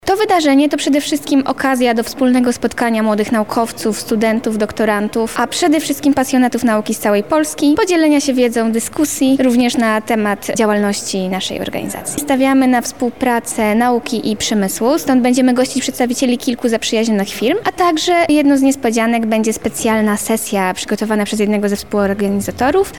Wywiad-zjazd.mp3